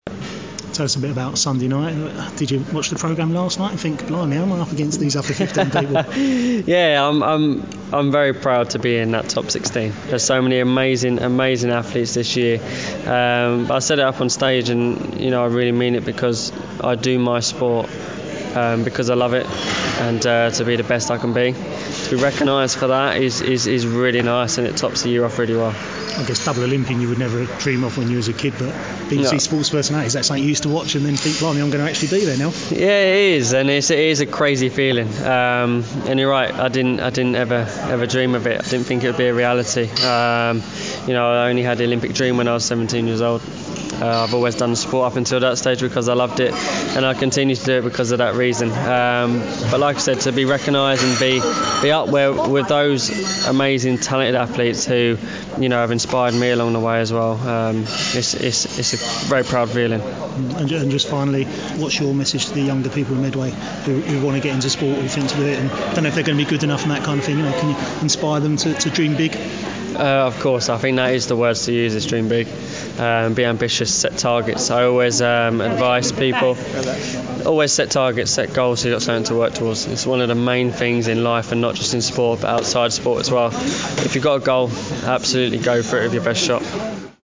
Max Whitlock at the Medway Sports Awards - 17/12/2016